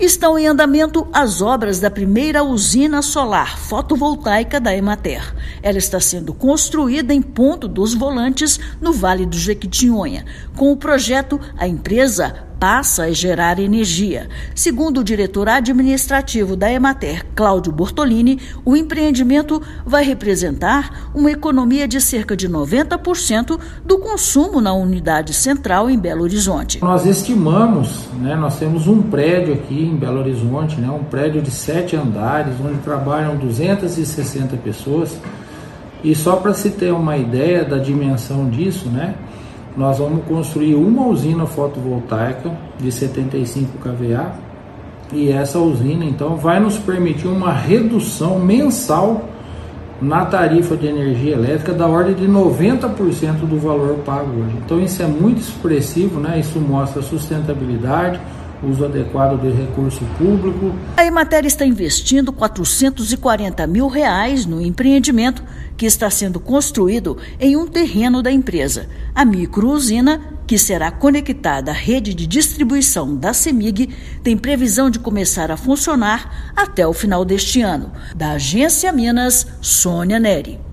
Já estão em andamento as obras da primeira microusina solar fotovoltaica da Empresa de Assistência Técnica e Extensão Rural do Estado de Minas Gerais (Emater-MG), em Ponto dos Volantes, no Vale do Jequitinhonha. Ouça matéria de rádio.